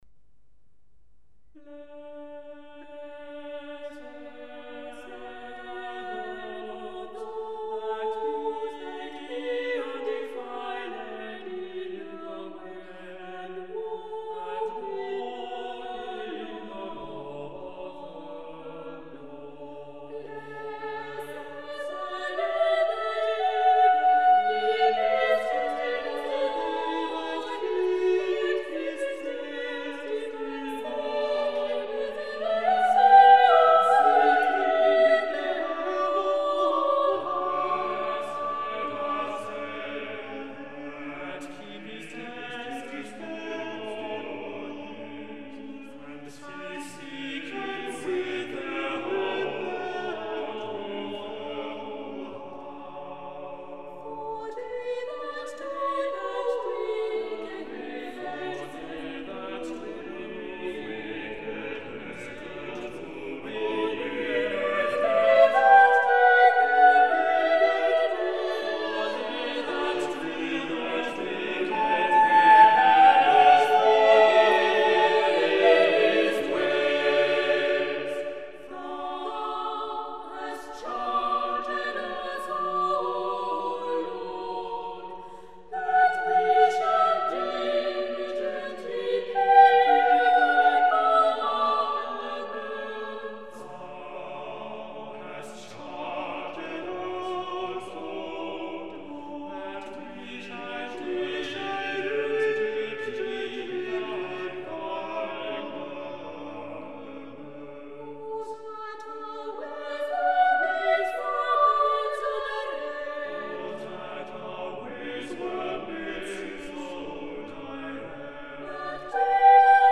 Listen to the Tallis Scholars perform "Blessed are Those that be Undefiled."
Blessed Are Those That Be Undefiled for 5 voices.mp3